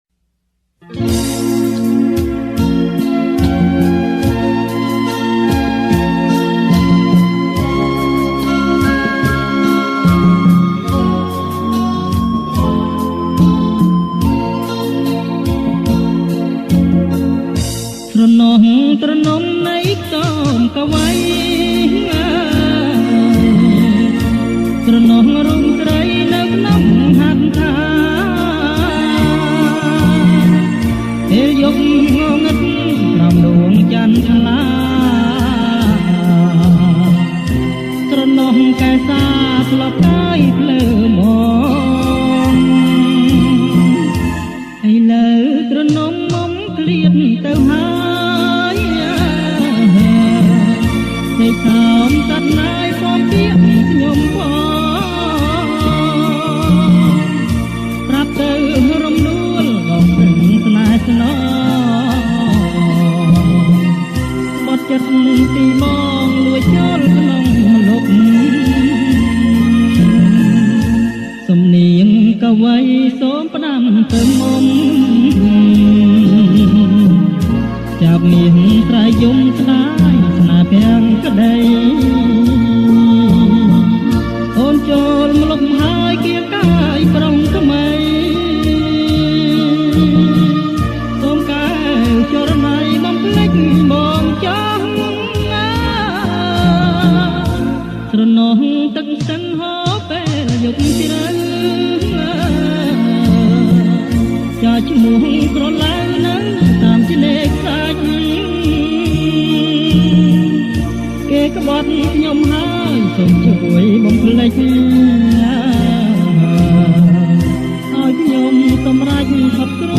• ប្រគំជាចង្វាក់ Blue Bolero